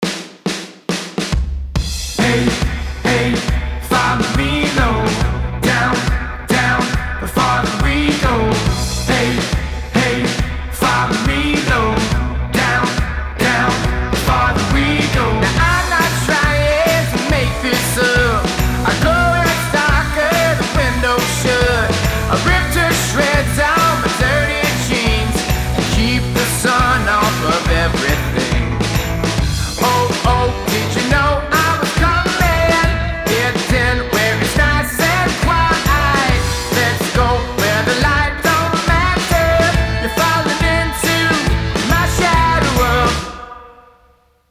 ритмичные
Alternative Rock
indie rock
Классный легкий рок)